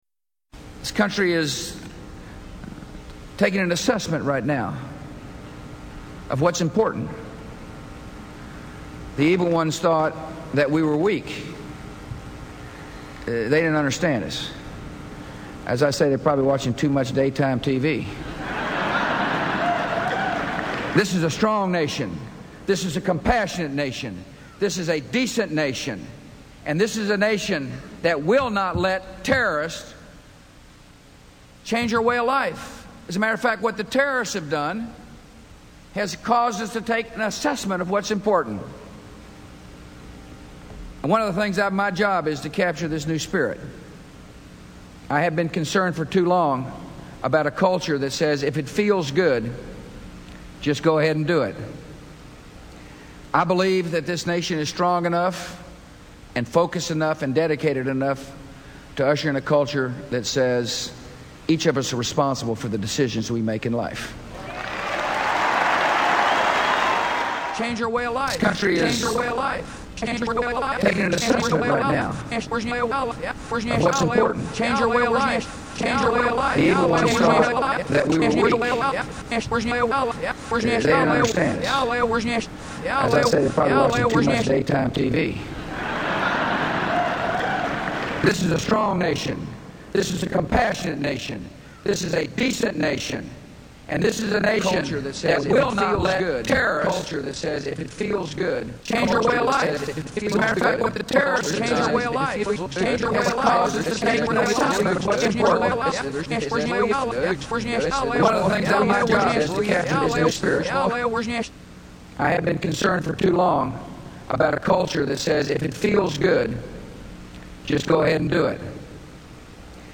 I decided that it might be interesting to write a piece that would make people aware of this phenomenon by crossing the threshold of inaudible reversal and audible reversal. I used a short speech that George Bush gave on terrorism and destroying American culture.
Because of the repeating of the speech, which gradually breaks down, the friendly experiencer listens carefully, grasping at meaning.